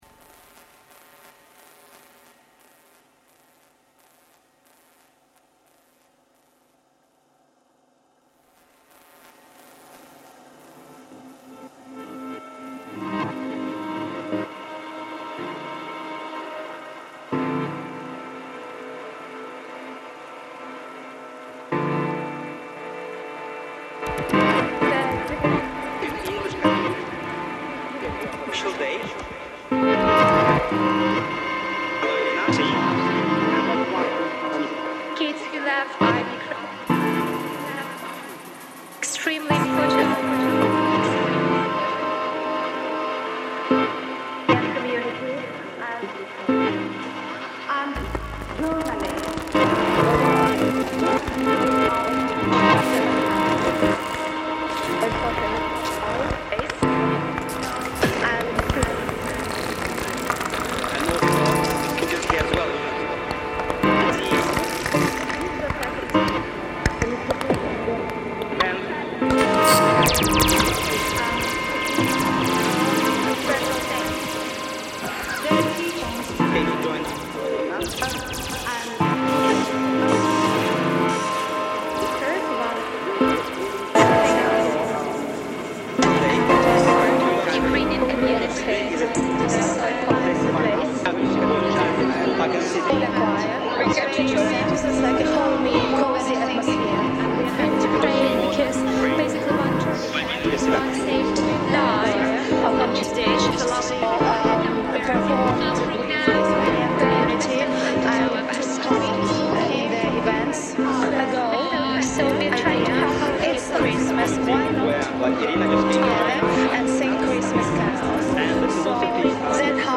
Made for Ukraine event reimagined